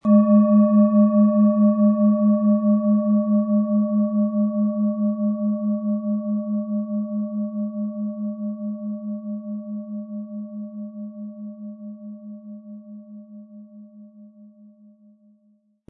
Sie sehen und hören eine von Hand gefertigt Neptun Klangschale.
Der kräftige Klang und die außergewöhnliche Klangschwingung der traditionellen Herstellung würden uns jedoch fehlen.
Mit einem sanften Anspiel "zaubern" Sie aus der Neptun mit dem beigelegten Klöppel harmonische Töne.
SchalenformBihar
MaterialBronze